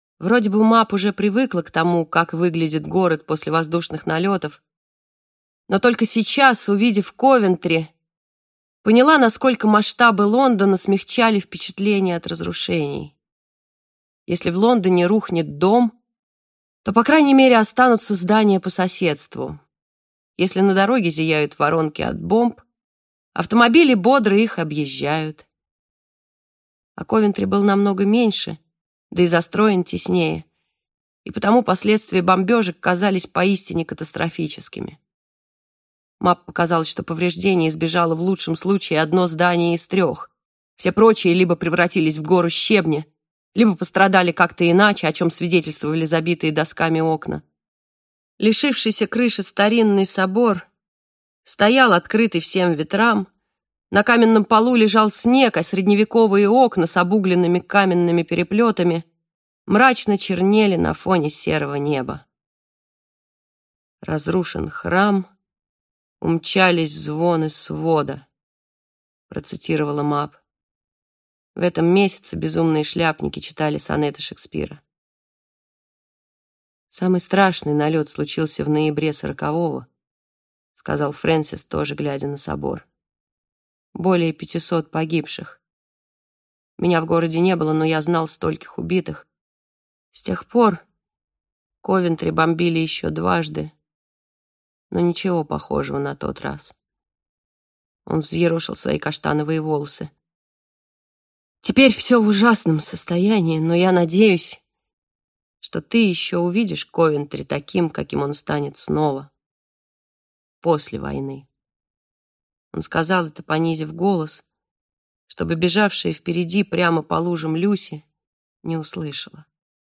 Аудиокнига Код Розы | Библиотека аудиокниг